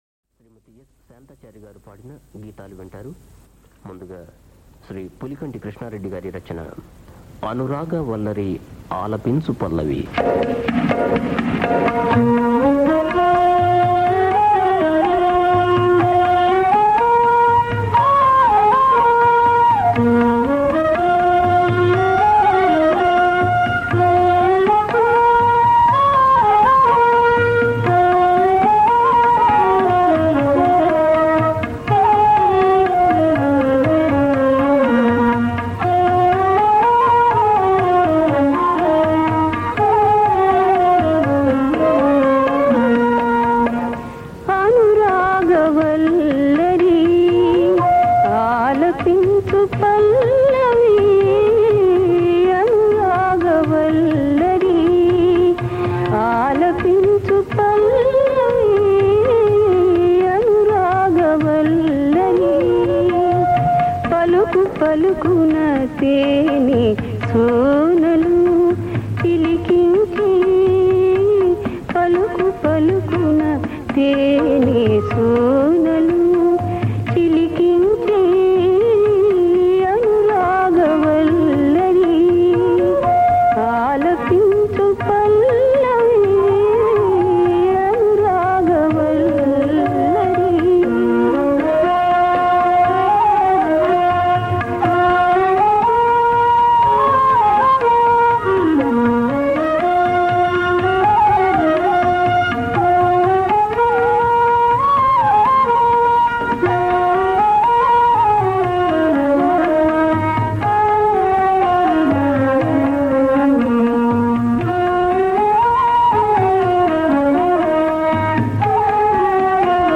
Ragam - Natabhairavi